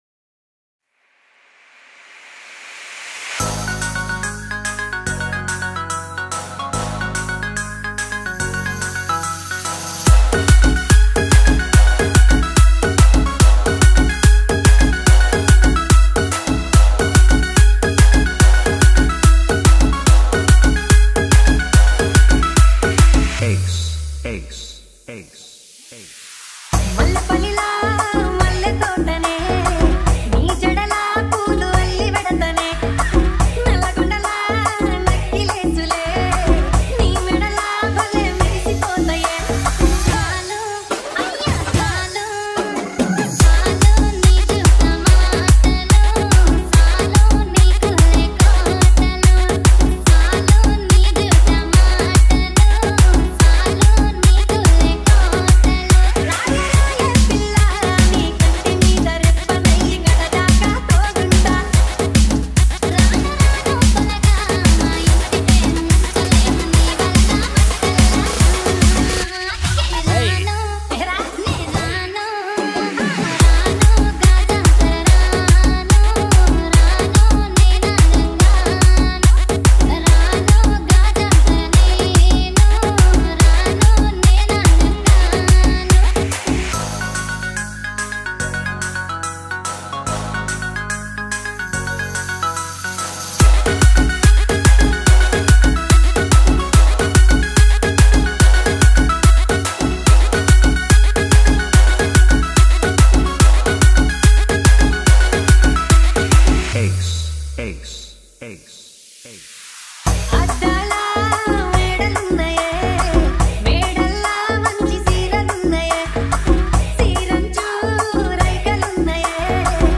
Edm Humming Bass Mix